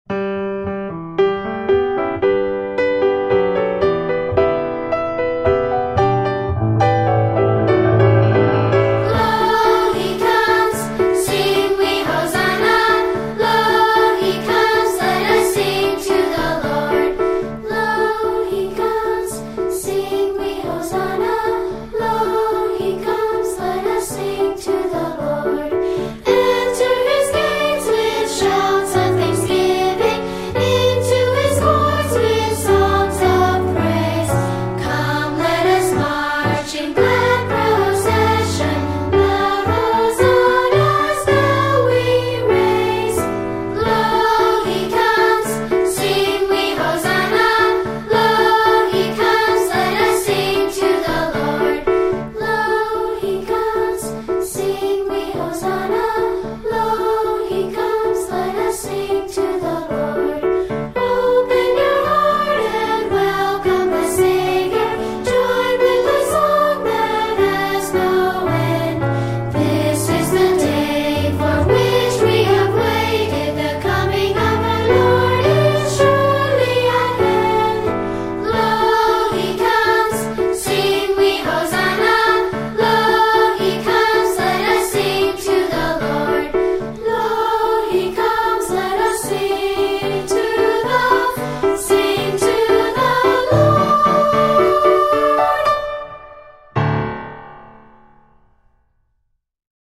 Preston Hollow Presbyterian Church: Children's - Choir